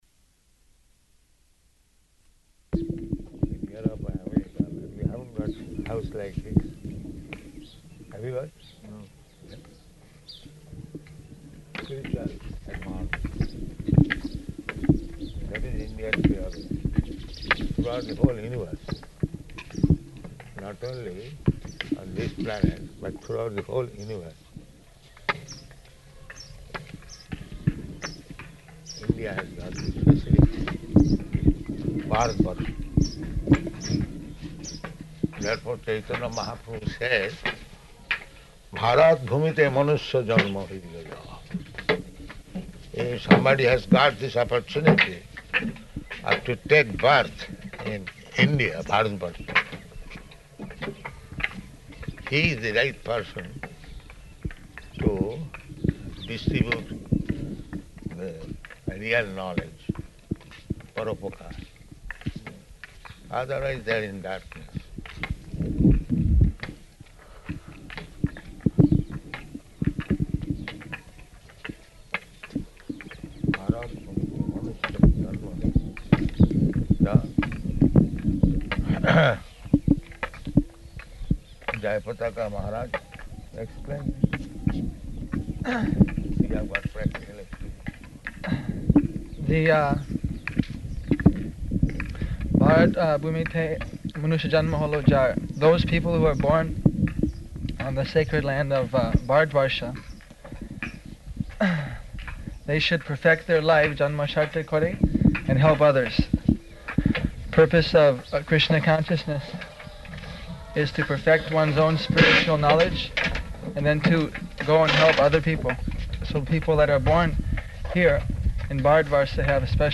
Morning Walk --:-- --:-- Type: Walk Dated: February 3rd 1976 Location: Māyāpur Audio file: 760203MW.MAY.mp3 Prabhupāda: ...in Europe and America, but they haven't got house like this.